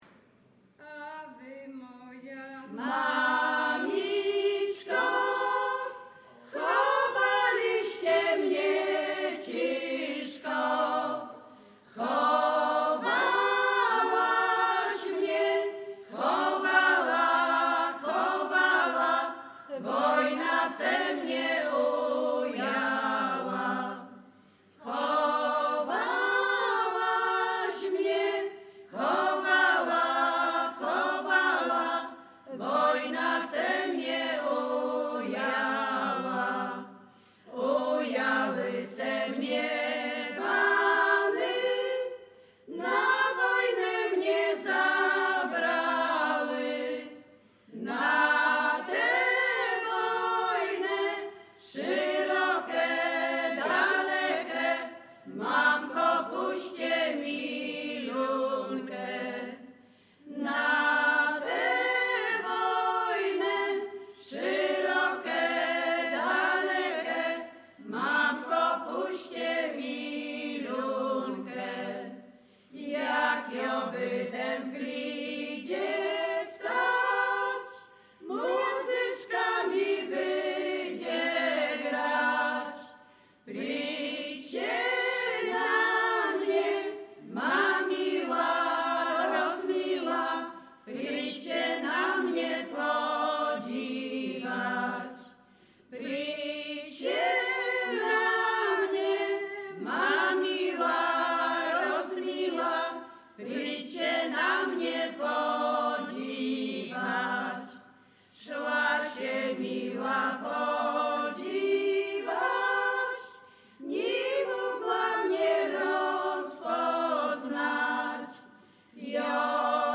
Zespół Dunawiec
województwo dolnośląskie, powiat lwówecki, gmina Lwówek Śląski, wieś Zbylutów
liryczne miłosne rekruckie wojenkowe